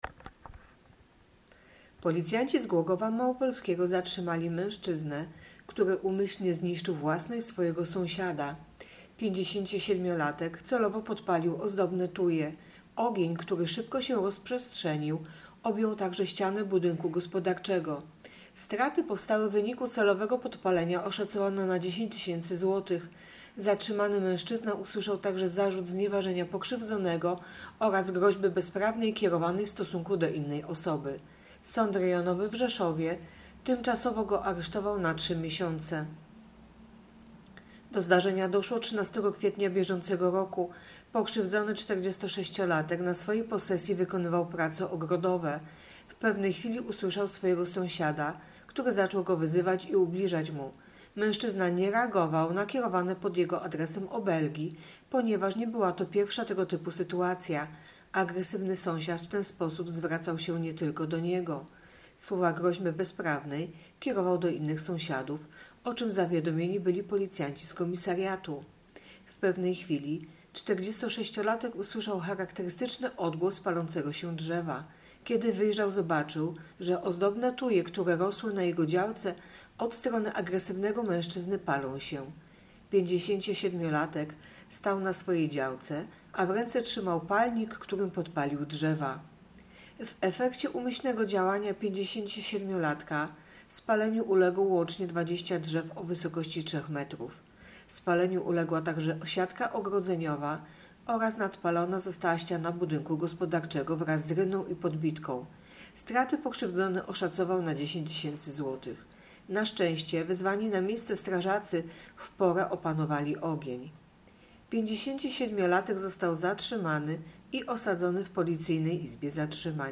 Nagranie audio Mówi